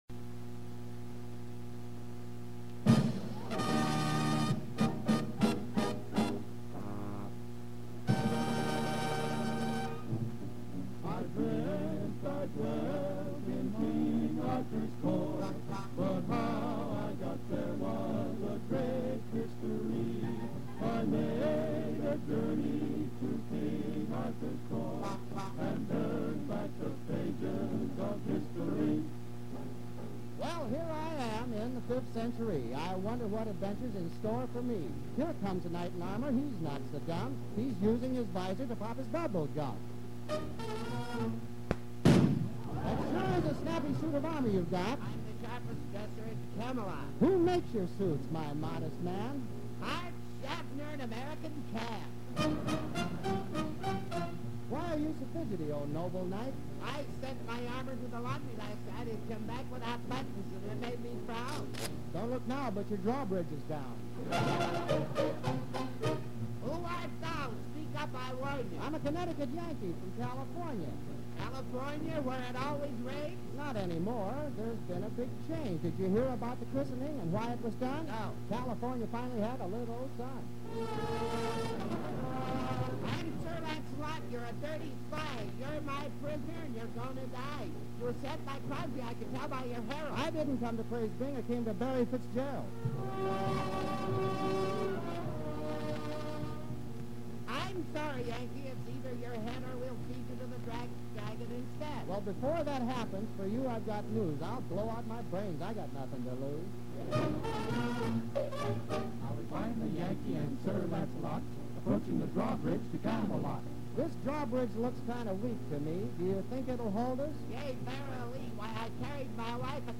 Zany Music Comedian